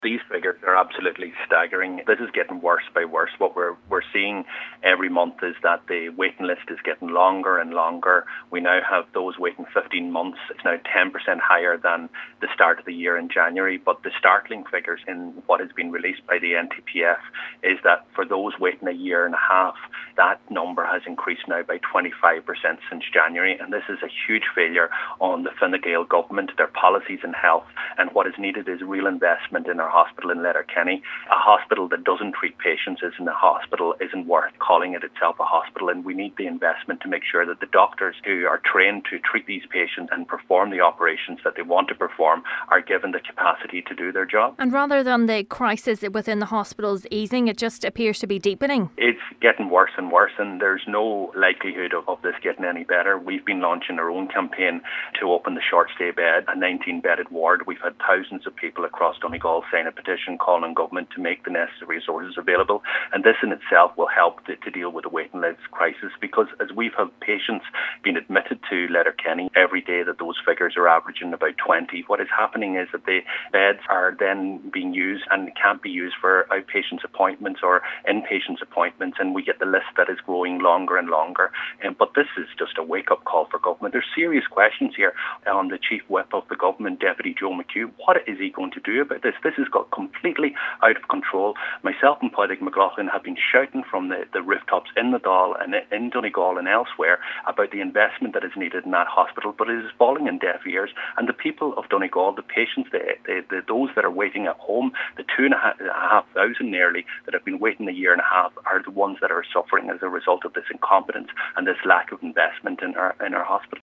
Donegal Deputy Pearse Doherty says these latest figures are a wake-up call for Government as the ever deepening health care crisis continues: